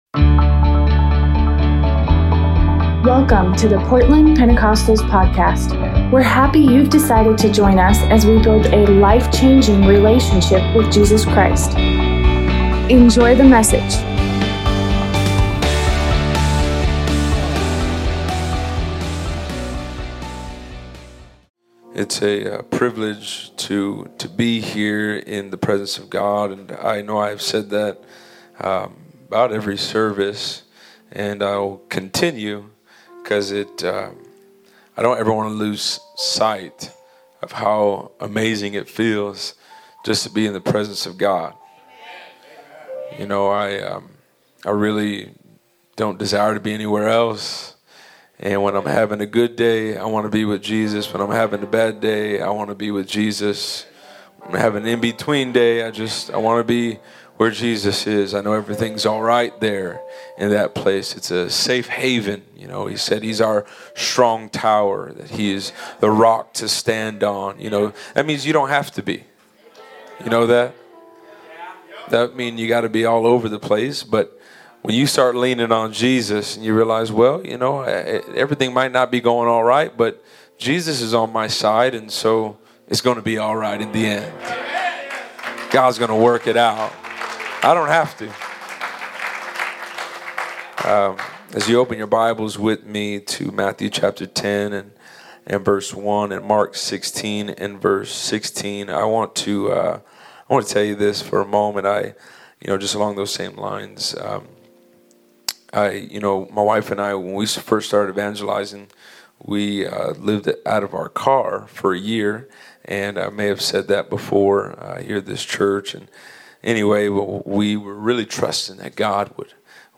Revival service